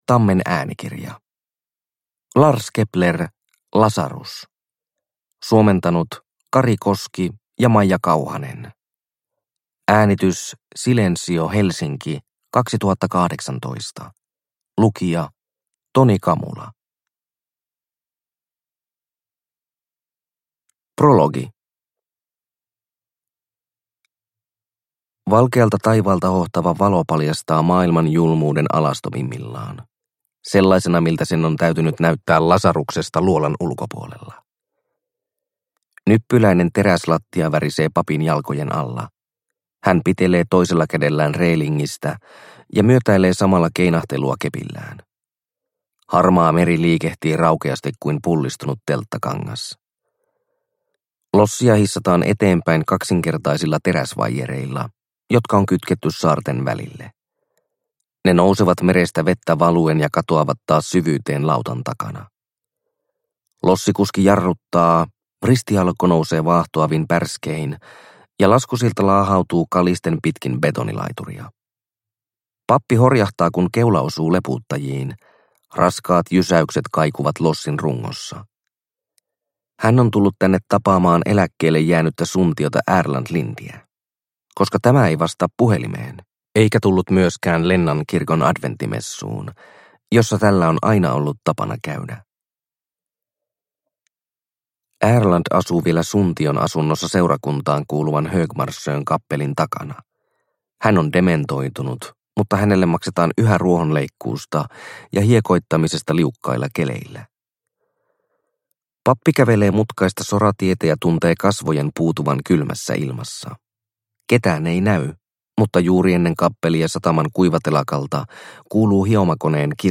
Lazarus – Ljudbok – Laddas ner